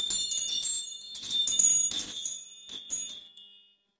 Wind Chimes
Delicate metal wind chimes tinkling randomly in a gentle outdoor breeze
wind-chimes.mp3